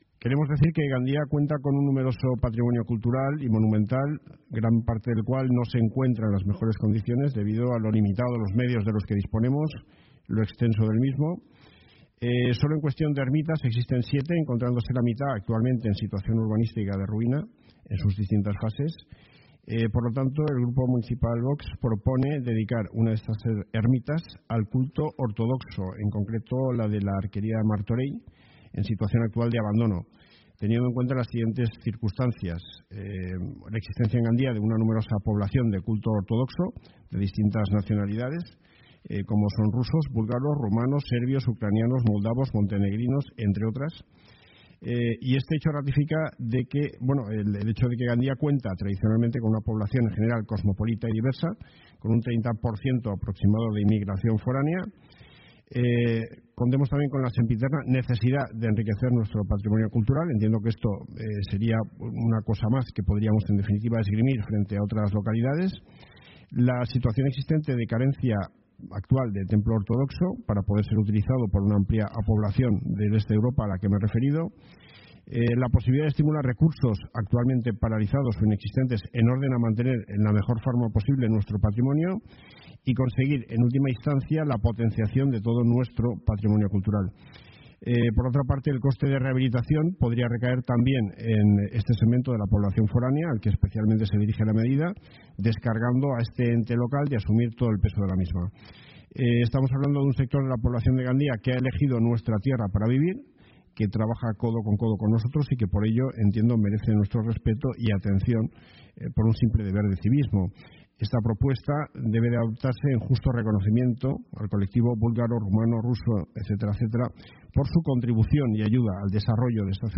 La carencia de un templo ortodoxo en Gandia ha salido a la palestra en el pleno ordinario del mes de febrero celebrado por la Corporación Municipal Gandiense donde el concejal de Vox, Manolo Millet ha pedido que la Ermita de Martorell sea destinada al culto ortodoxo.
Escucha aquí la intervención de Millet (VOX) y la respuesta de la edila de Patrimonio, Alícia Izquierdo (audio)